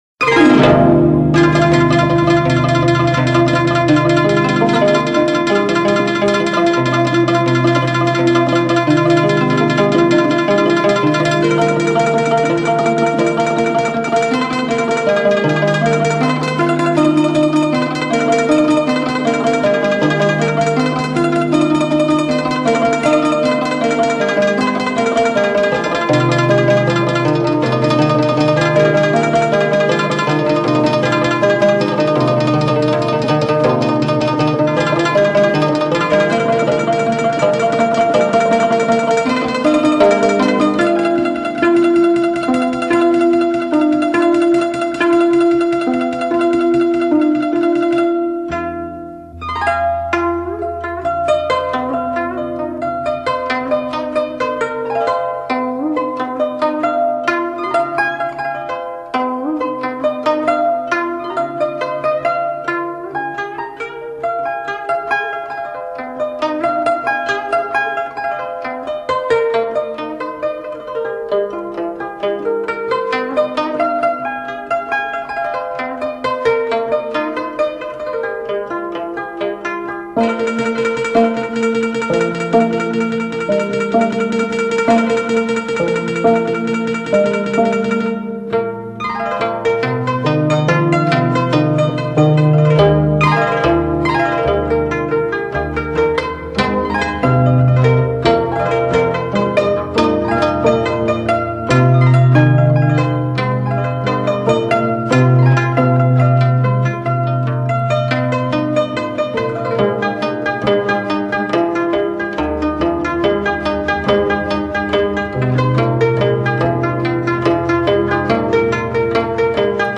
11/21/2007]古筝曲《将军令》